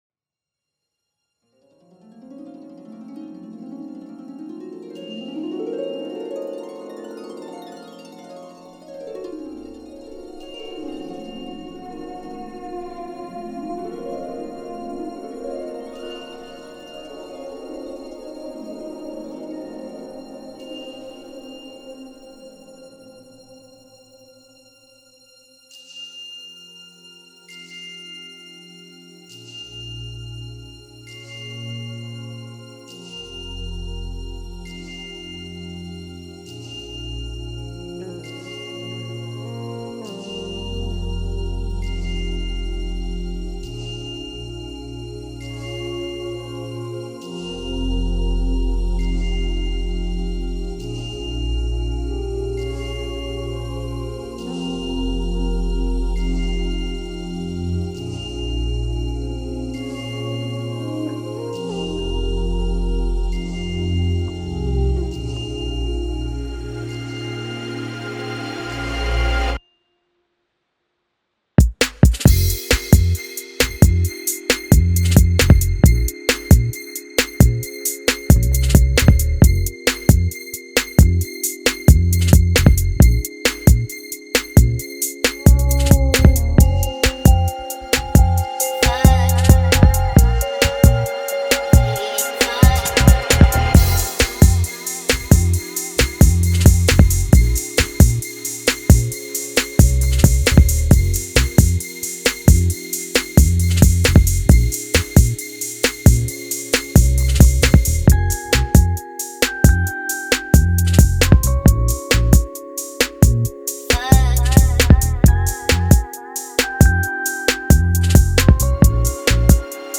official instrumental
Pop Instrumentals